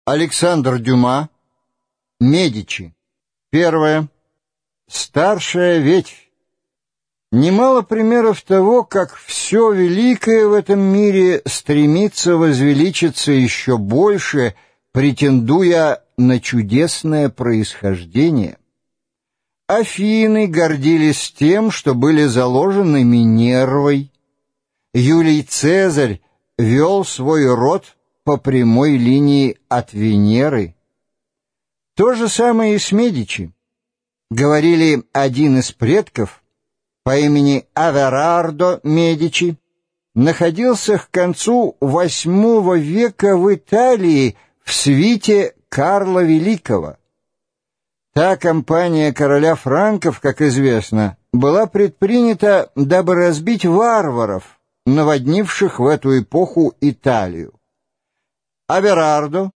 Аудиокнига Медичи. Роман-хроника | Библиотека аудиокниг